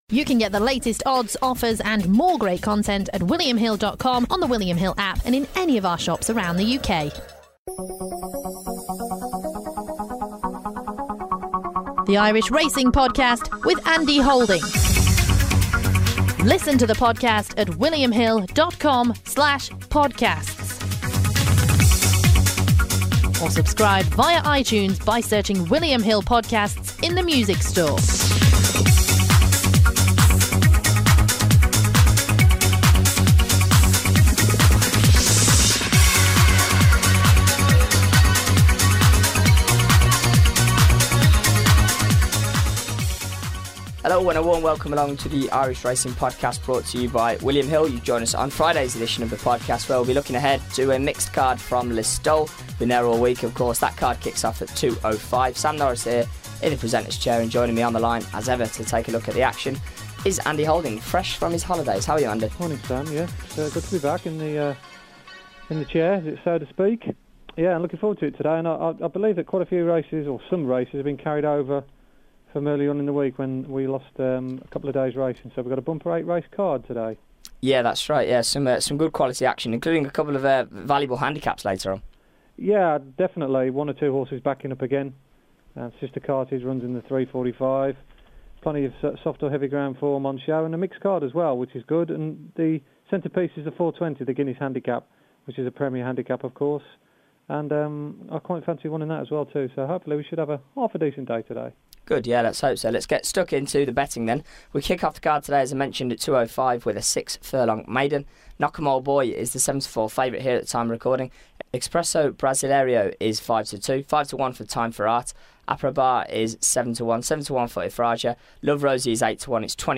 on the line to offer his selections for every race at the meeting.